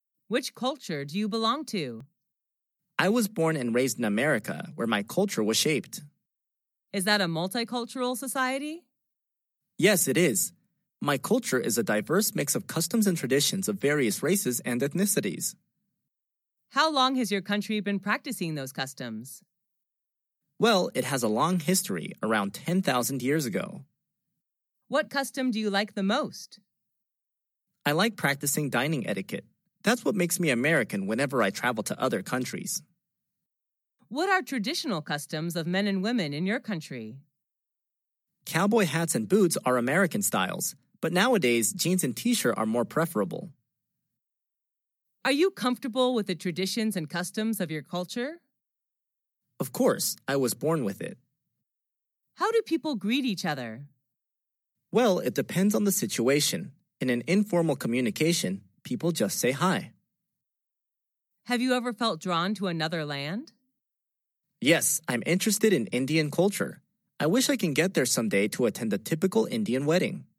Sách nói | QA-53